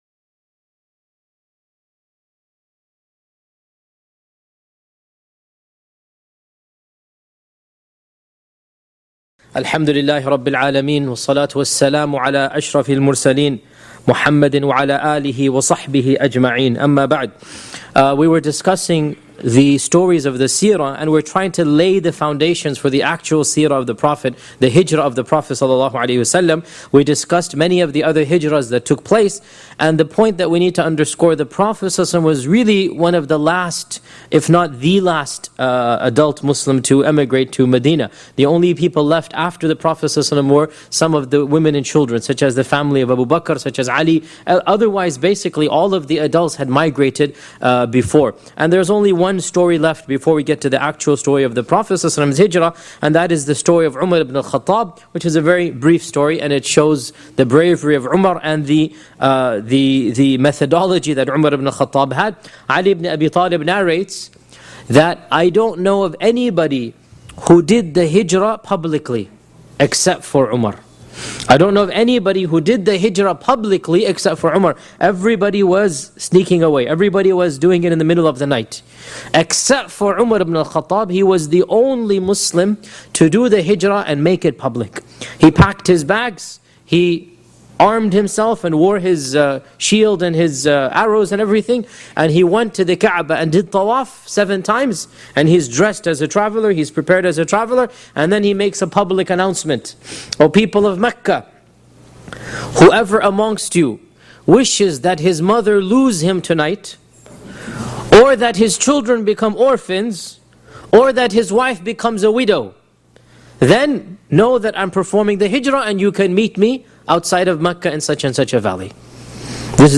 621 views High Quality: Download (330.58 MB) Medium Quality: Download (50.96 MB) MP3 Audio (01:07:50): Download (51.33 MB) Transcript: Download (0.29 MB) Seerah of Prophet Muhammad 26 Shaykh Yasir Qadhi gives a detailed analysis of the life of Prophet Muhammed (peace be upon him) from the original sources. Title: The Hijrah – Emigration to Madinah Study the biography of the single greatest human being that ever walked the surface of this earth, whom Allah sent as a Mercy to Mankind. This lecture was recorded on 15th March, 2012 Shaykh Yasir Qadhi gives a detailed analysis of the life of Prophet Muhammed (peace be upon him) from the original sources.